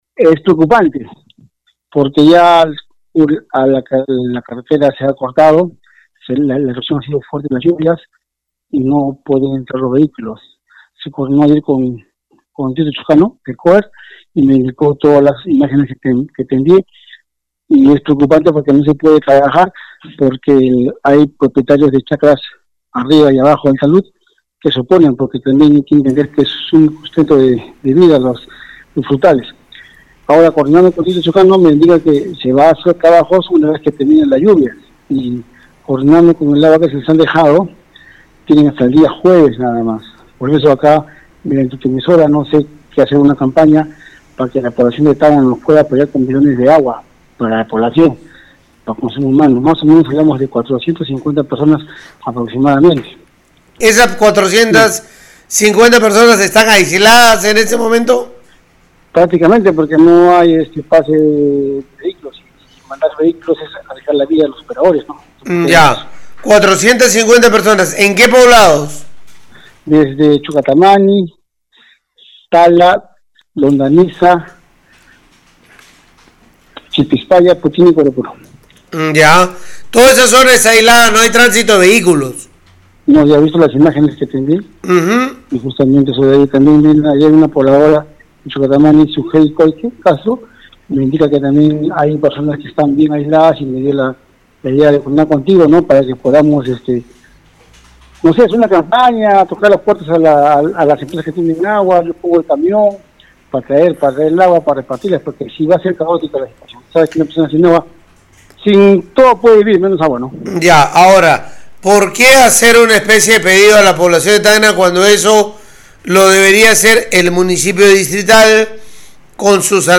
kenny-alcalde-alcalde-de-tarata.mp3